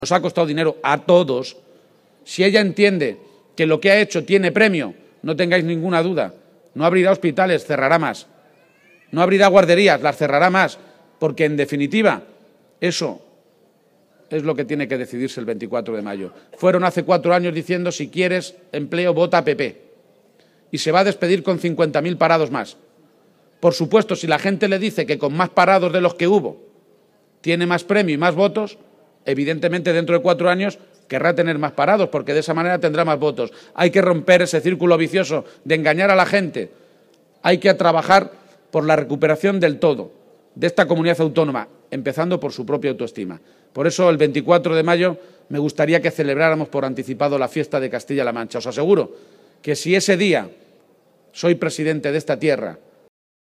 En el acto público del PSOE en Caudete, también anunció que si el próximo 31 de Mayo, Día de Castilla-La Mancha, es presidente de la Comunidad Autónoma,- gracias al voto mayoritario de la ciudadanía-, su discurso será “incluyente”, remarcando que la próxima Legislatura representará “la esperanza de una Región que si ha sido capaz de llegar tan lejos en la historia, partiendo de una posición tan atrasada, podremos sobreponernos al bache que ha supuesto Cospedal”.